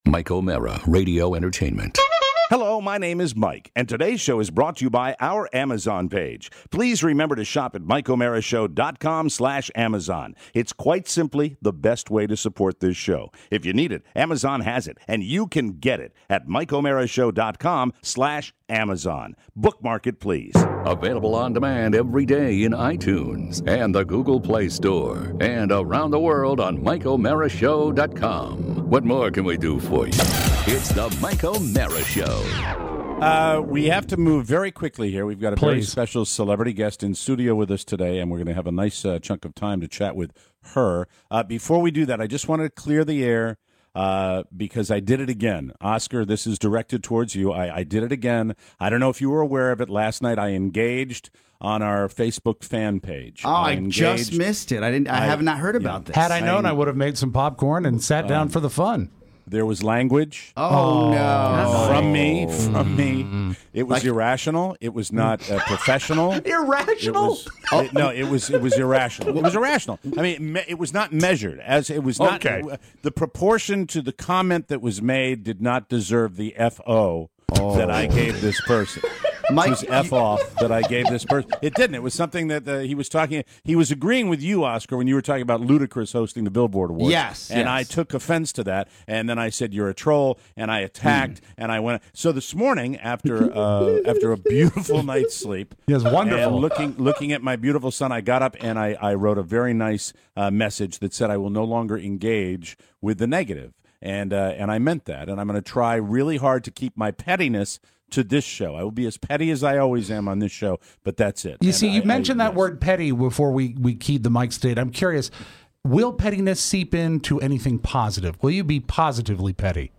In studio… Cindy Williams! Plus, pettiness… the politics of blockage… bikes… and the sexiest mic.